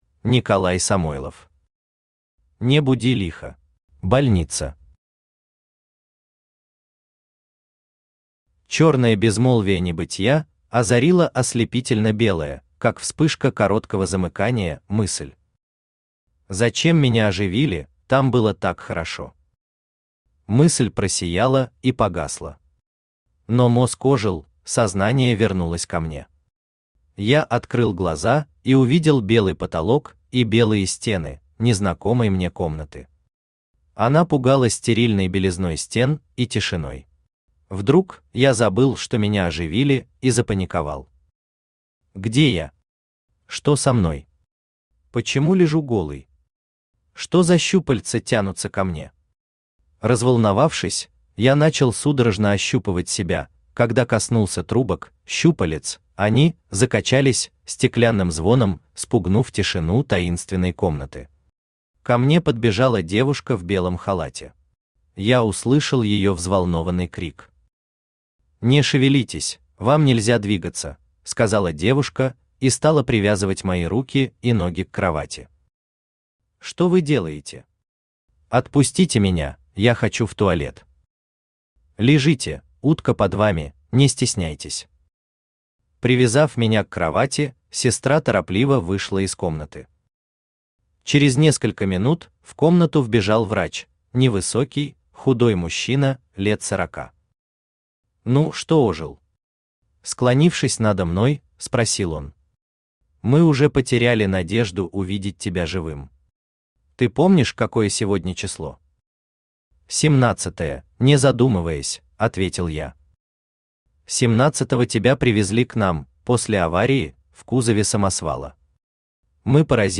Аудиокнига Не буди лихо | Библиотека аудиокниг